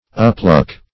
Uppluck \Up*pluck"\
uppluck.mp3